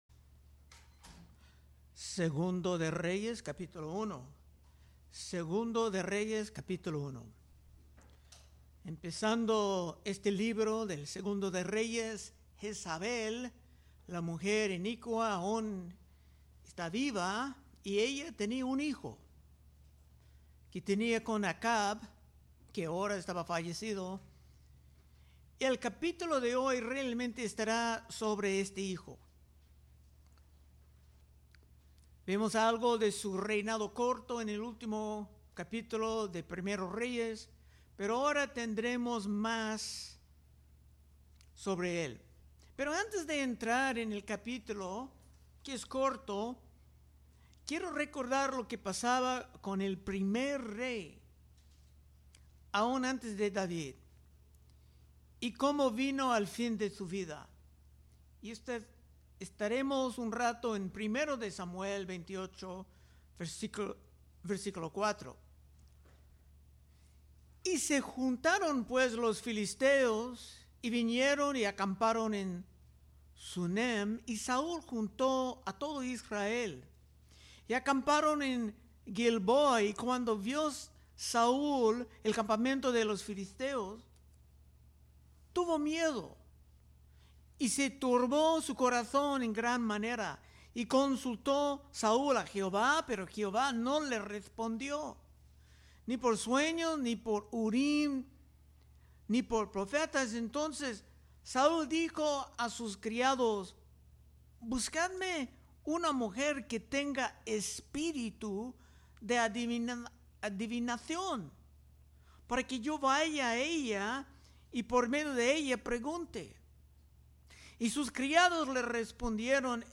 Predicaciones De Exposición Libro De 2 Reyes